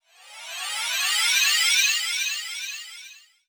MAGIC_SPELL_Metallic_Rain_Up_stereo.wav